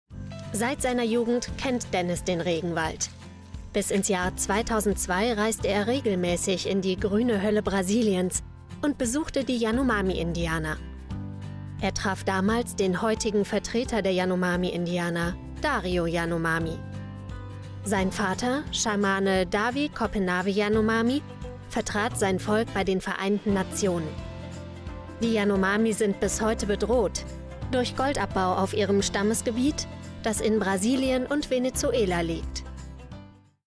Duits
Jong, Toegankelijk, Veelzijdig, Warm, Zacht
Audiogids